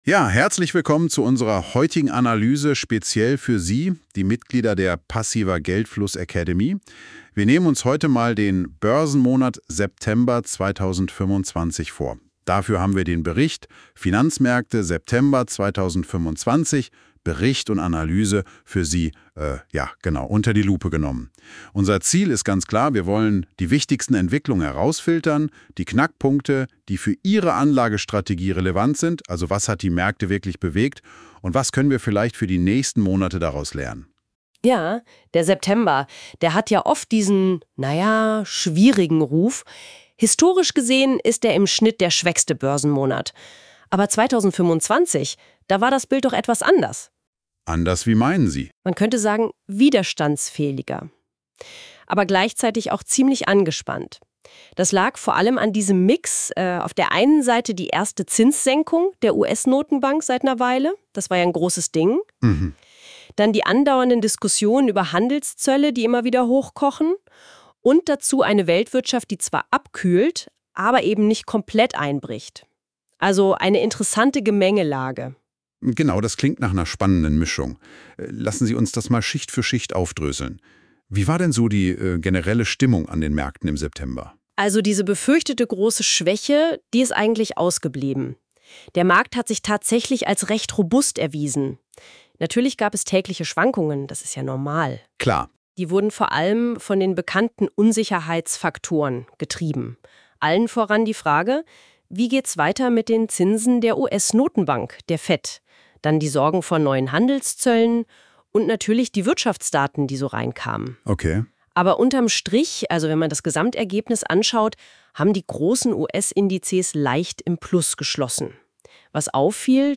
(nächste Aktualisierung: 01.11.2025) Was hat im September 2025 die Börse bewegt und was waren wichtige Wirtschaftsereignisse? Zwei KI-Moderatoren fassen die relevanten Geschehnisse aus dem September 2025 zusammen.